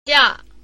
チャ（ကျ）」=味がよく出ている